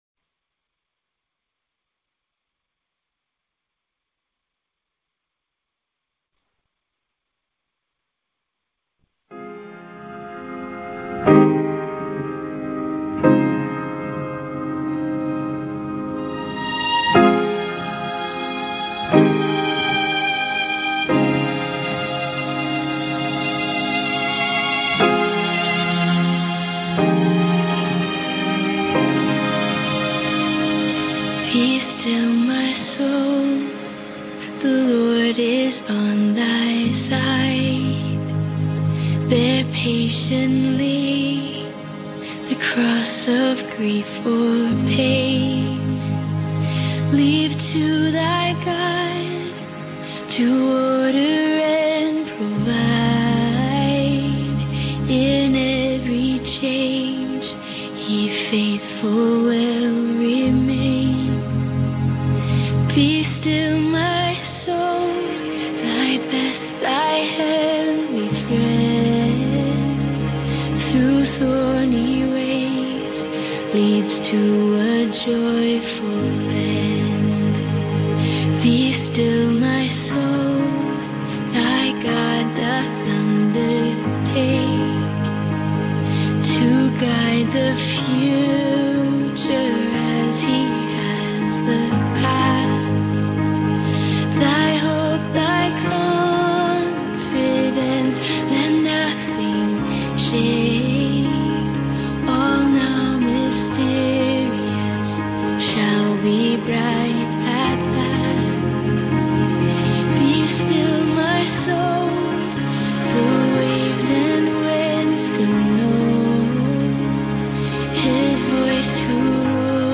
Conference Call Fellowship Date